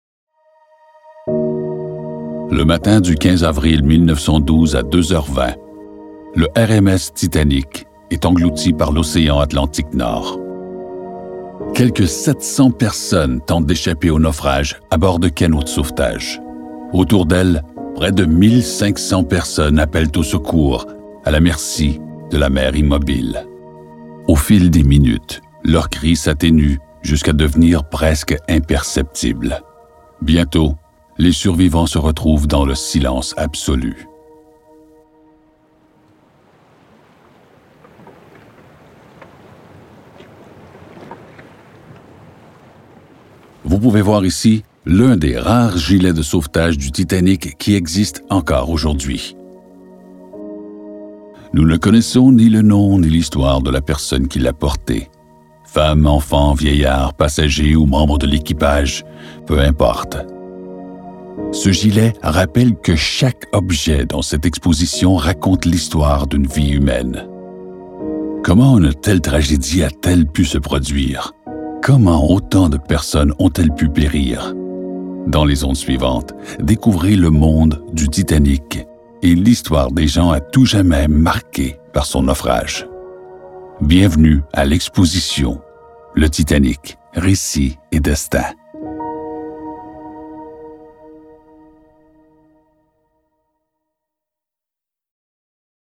• Le déploiement muséologique extrêmement fort et saisissant génère une vive émotion, décuplée grâce à un audioguide diffusant une trame narrative des plus bouleversantes, redonnant vie à ceux et celles qui ont vécu la funeste nuit du 14 au 15 avril 1912.
titanic-audioguide-nauffrage.mp3